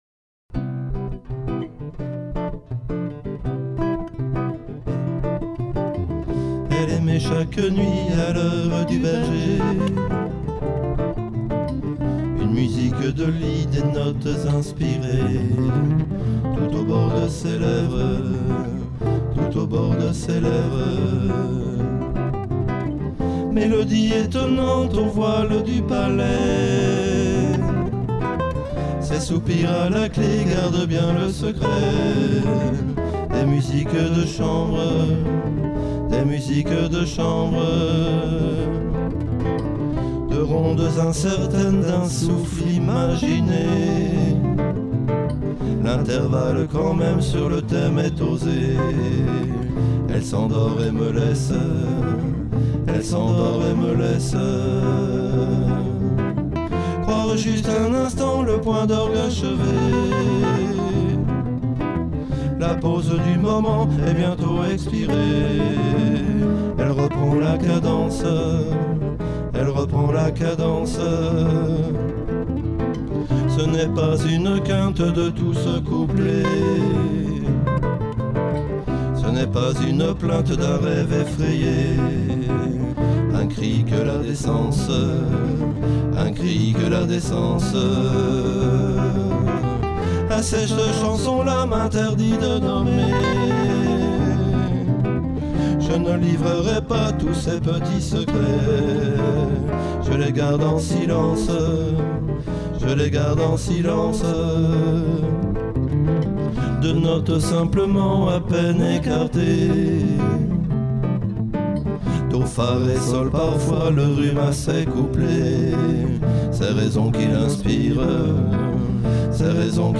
chant, guitare